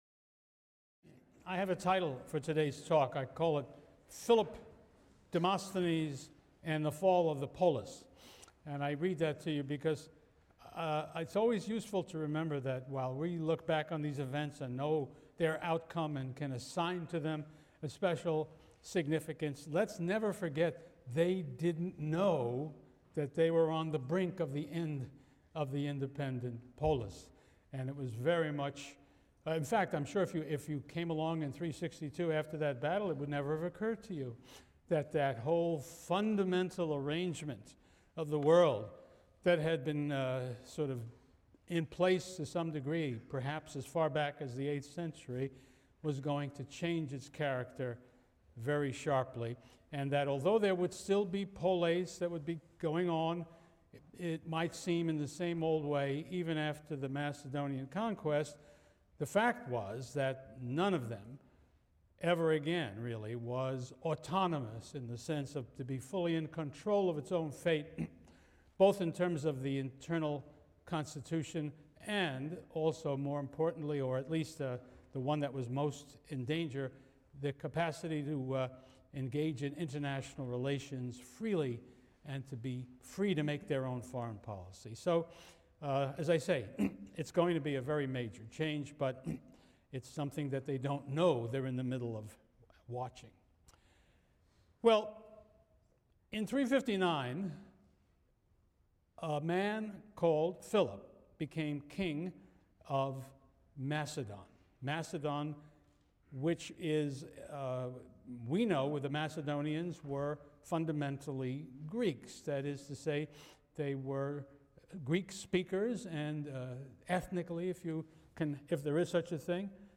CLCV 205 - Lecture 24 - Twilight of the Polis (cont.) and Conclusion | Open Yale Courses